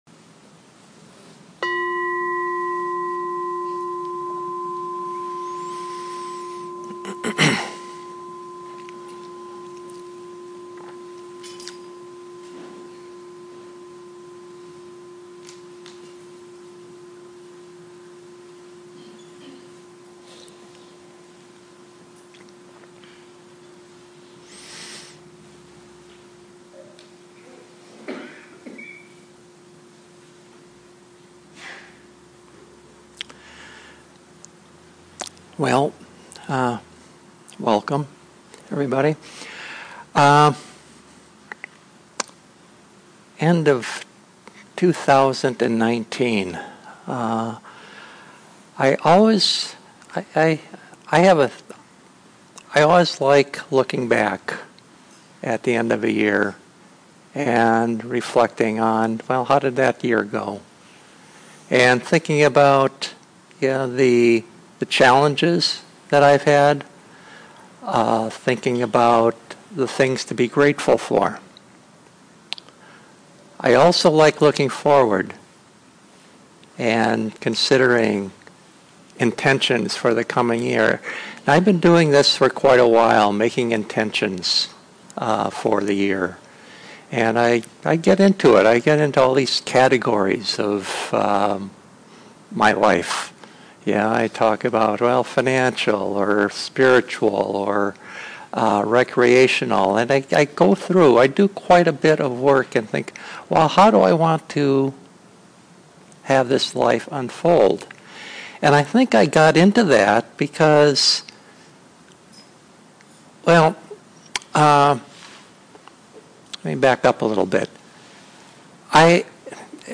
2019-12-30 Venue: SIMS at University Friends Meeting House